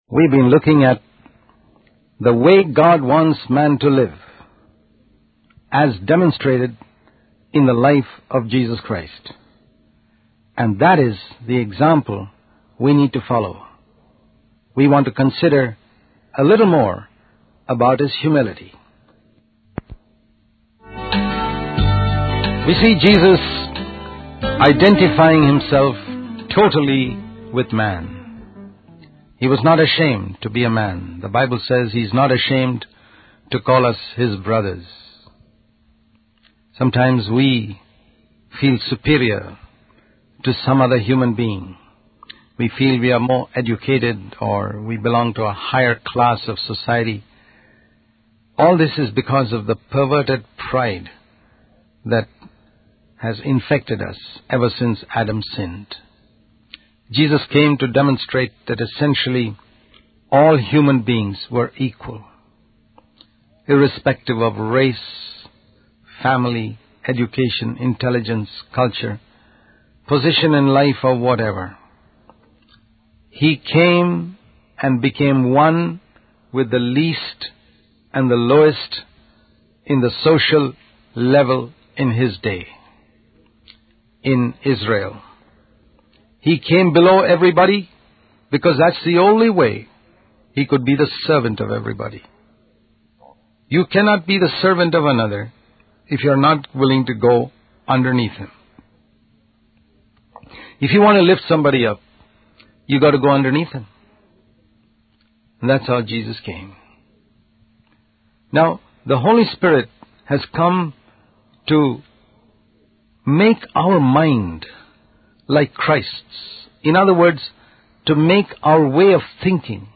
In this sermon, the speaker discusses the importance of humility in the life of a Christian. He emphasizes that Jesus demonstrated humility by washing the disciples' feet, a task typically done by servants. Jesus identified himself with humanity and considered all human beings equal, regardless of their race, education, or social status.